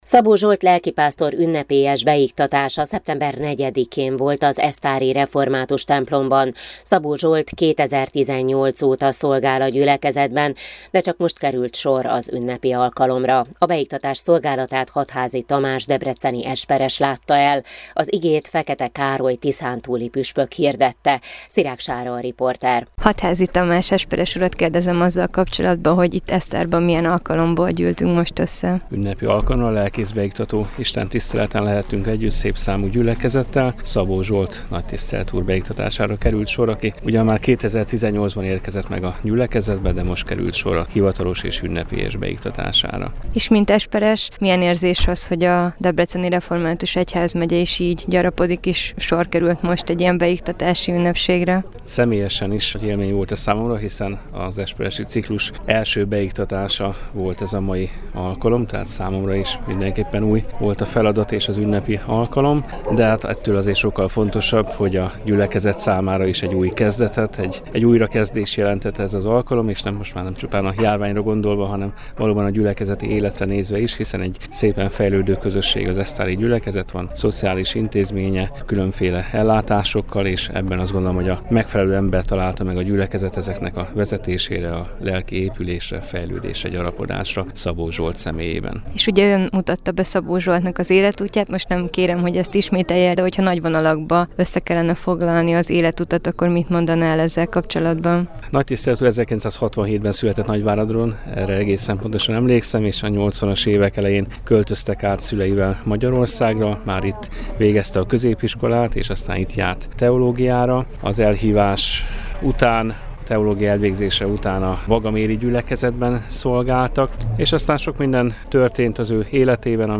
Az alkalmon Fekete Károly, a Tiszántúli Református Egyházkerület püspöke hirdette Isten igéjét.